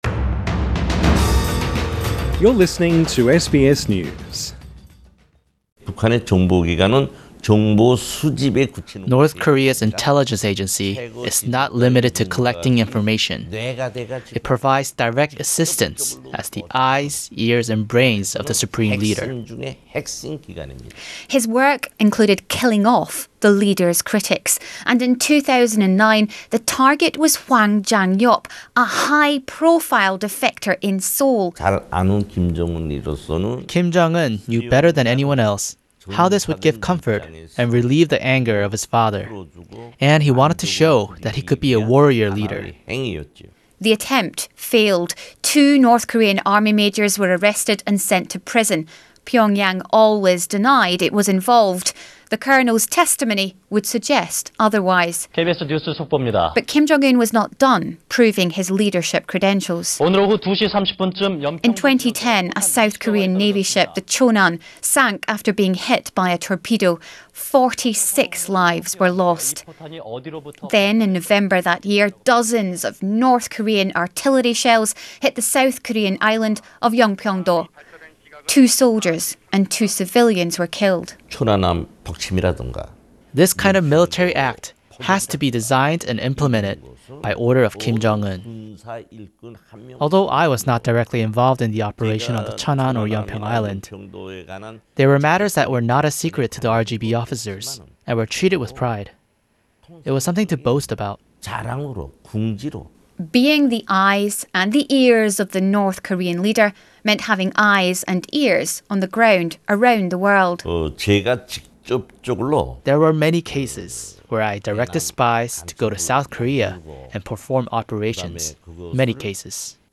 A senior army defector gives first interview about life in North Korea